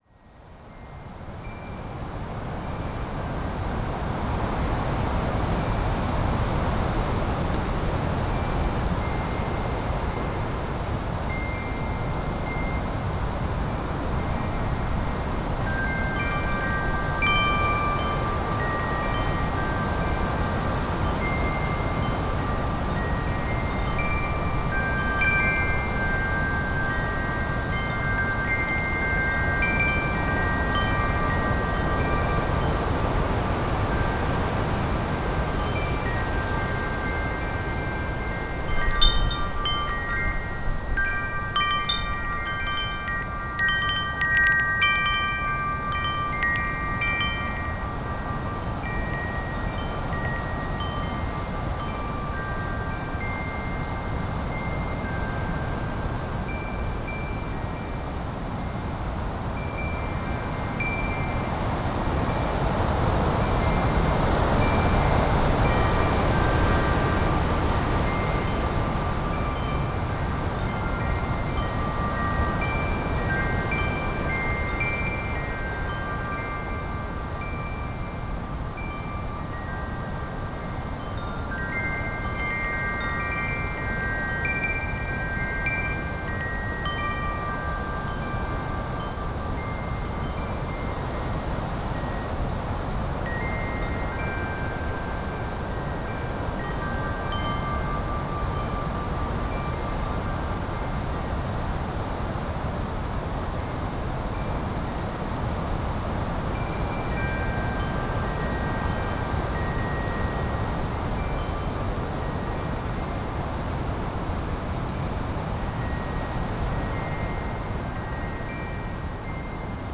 Wind Chimes in GALE over wooded valley - Pluto - excerpt
Category 🌿 Nature
chimes Devon Drewsteignton England field-recording gale gusts January sound effect free sound royalty free Nature